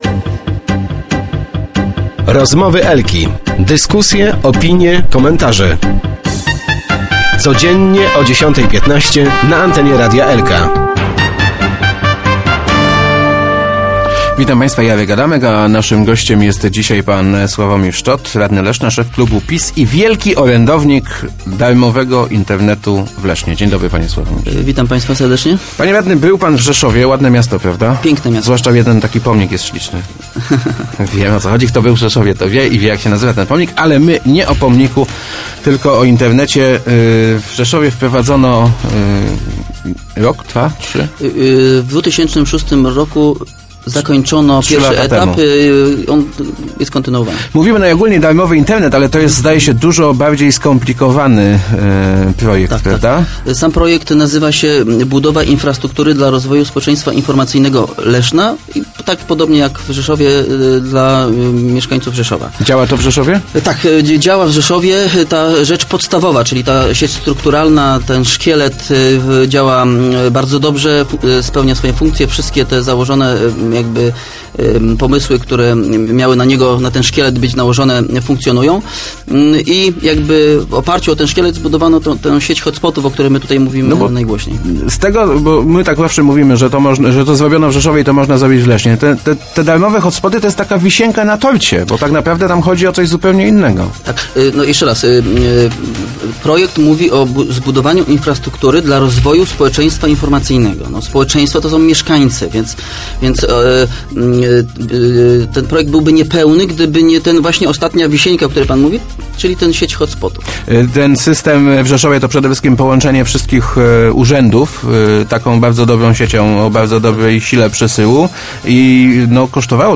thumb_szczotstudio.jpgLeszno. Jestem pod wrażeniem – mówił w dzisiejszych Rozmowach Elki radny Leszna Sławomir Szczot, podsumowując wizytę w Rzeszowie, gdzie obserwował wprowadzony przed trzema laty system „Internetu Obywatelskiego”.